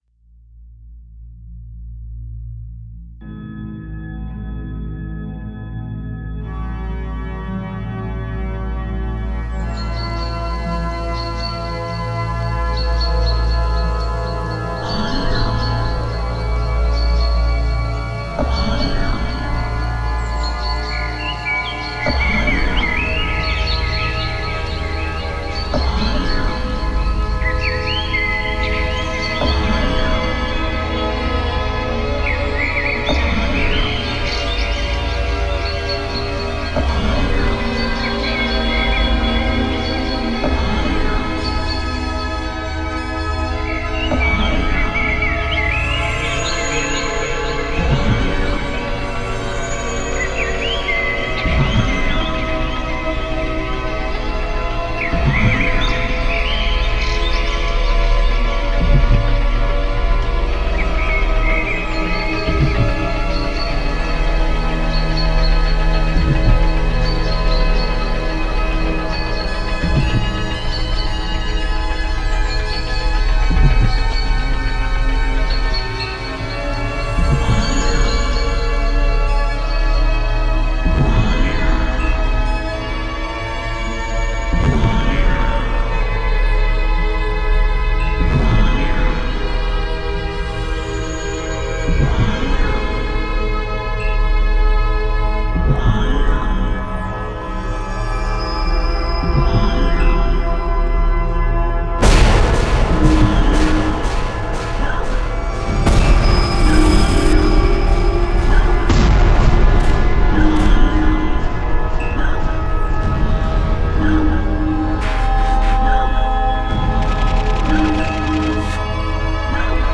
Adagio Skeptizismus Sed